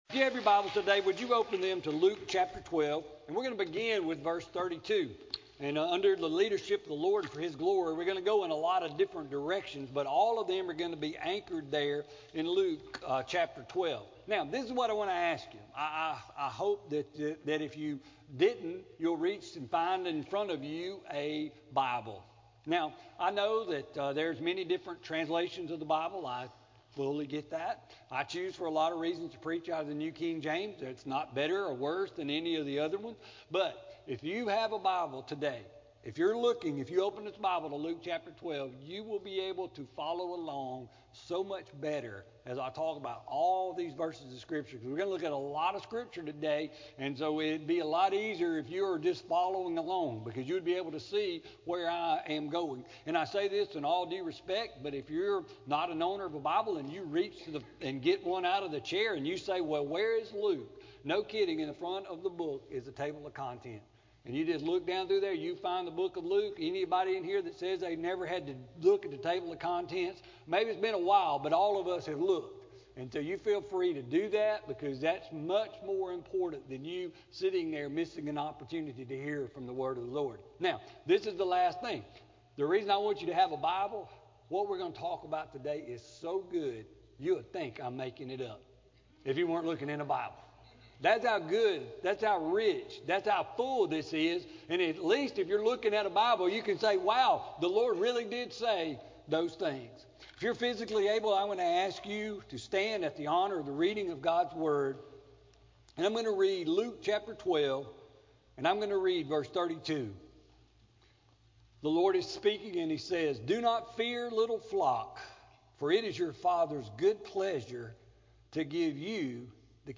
Sermon-4-19-15-CD.mp3